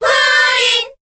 Category:Crowd cheers (SSBB) You cannot overwrite this file.
Jigglypuff_Cheer_Korean_SSBB.ogg.mp3